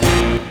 Hit 13-A#2.wav